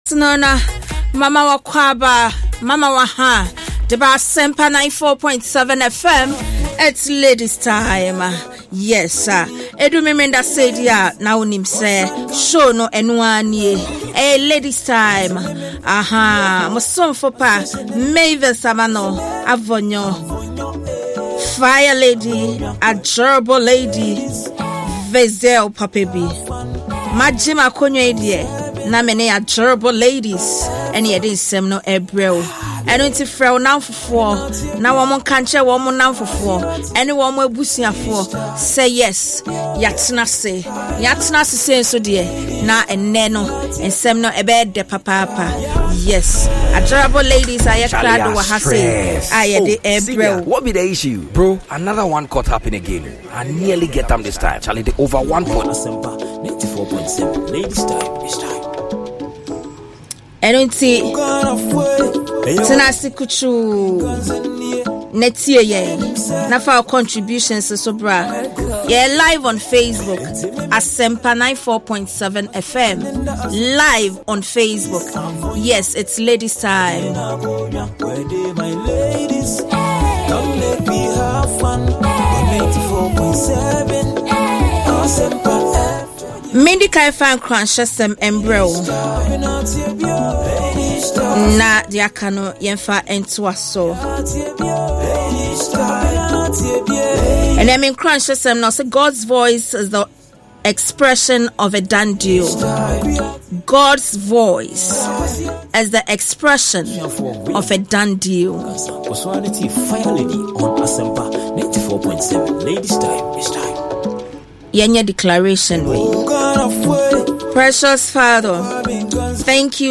A fun-filled discussion of sports and sporting issues by female celebrities.